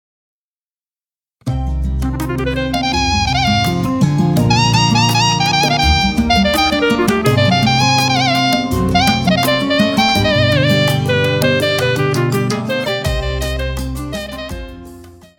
• Type : Instrumental
• Bpm : Andante
• Genre : New Age / Oriental